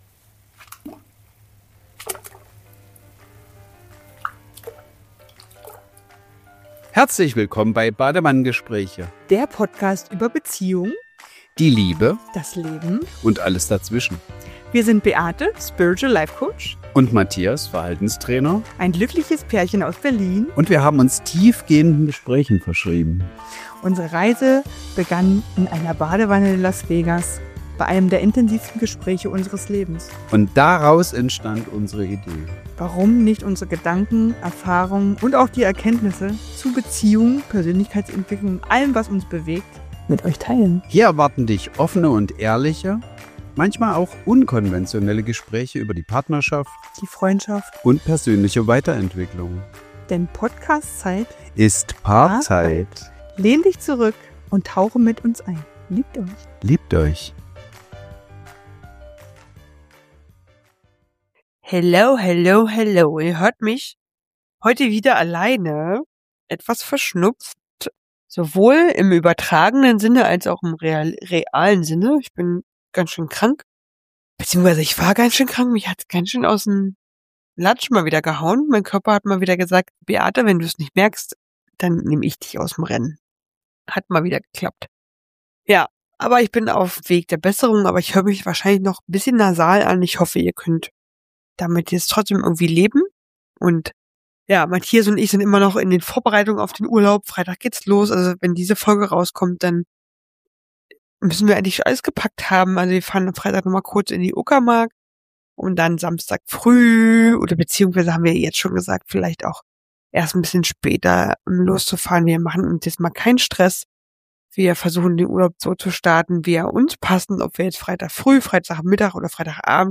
In dieser Solo-Folge von Badewannengespräche nehme ich dich mit in eine sehr persönliche Erfahrung – eine Situation, in der ich mich klein gemacht und übergangen gefühlt habe.